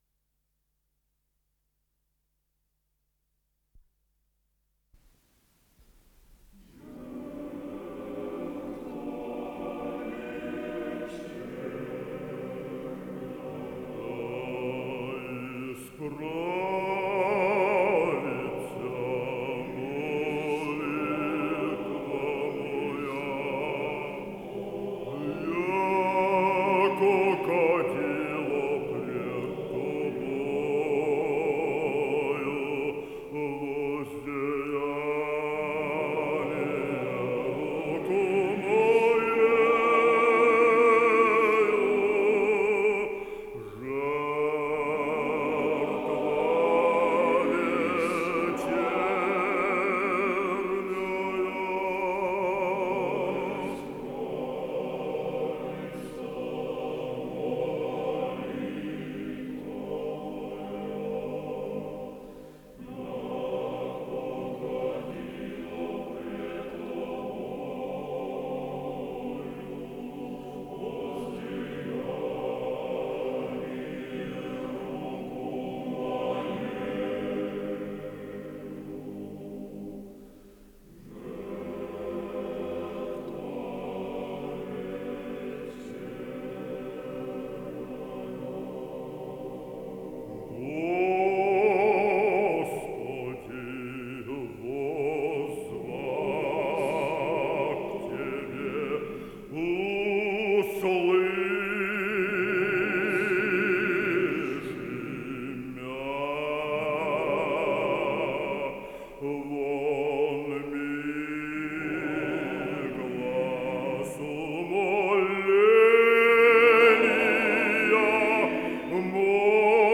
ПодзаголовокИз литургии преждеосвещенных даров, на старославянском языке
ИсполнителиАлександр Ведерников - бас
АккомпаниментАкадемический Большой хор Гостелерадио СССР
Скорость ленты38 см/с
Тип лентыORWO Typ 106